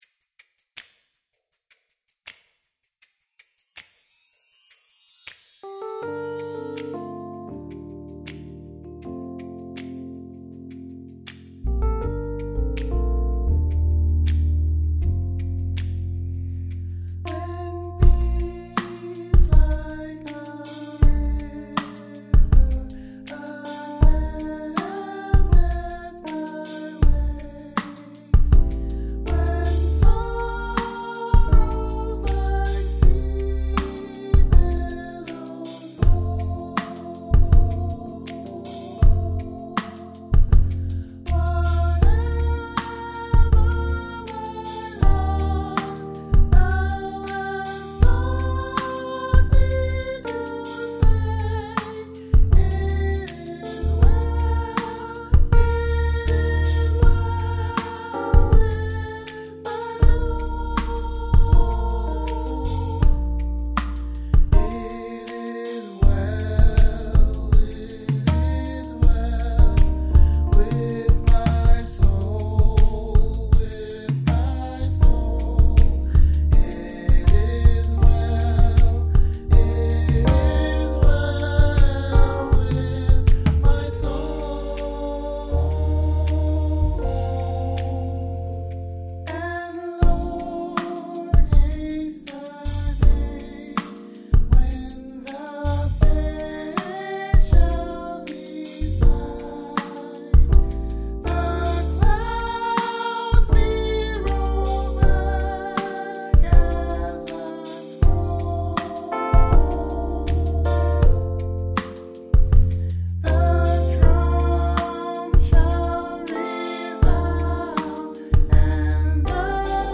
IT IS WELL: ALTO